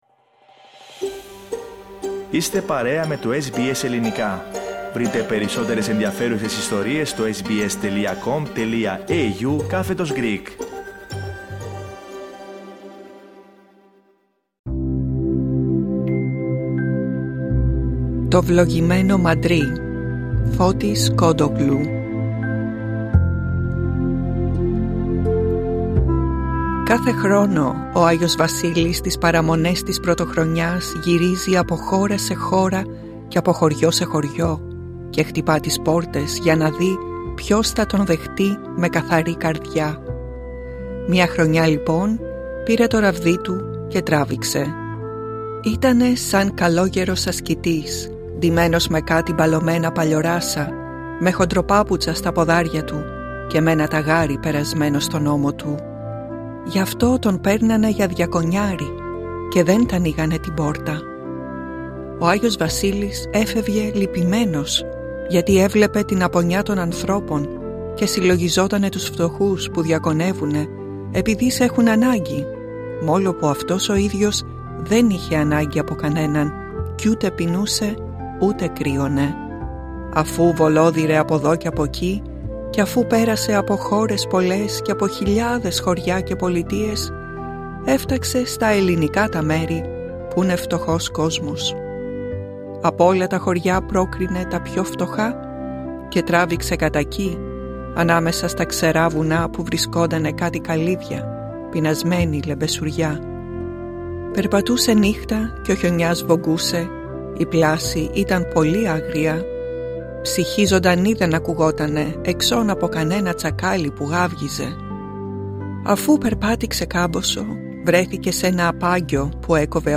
Ελληνικά διηγήματα